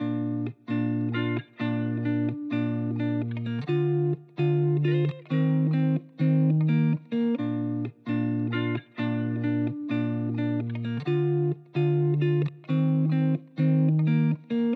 MELODY PREVIEWS